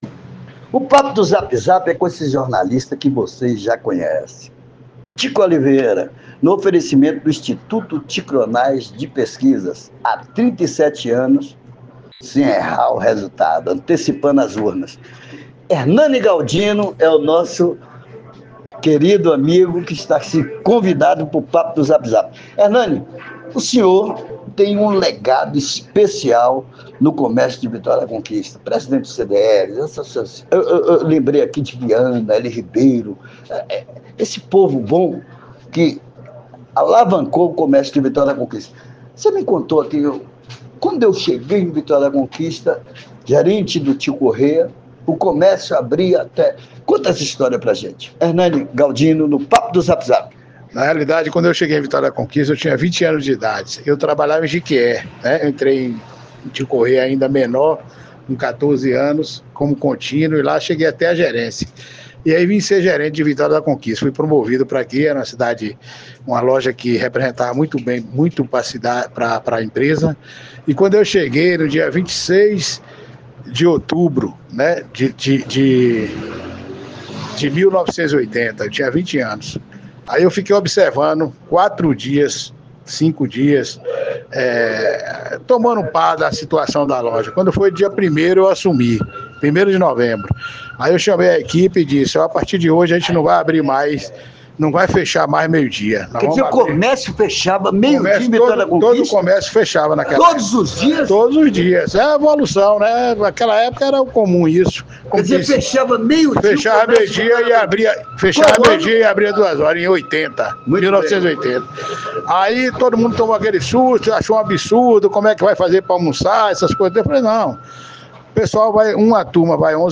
teve uma conversa envolvente com o jornalista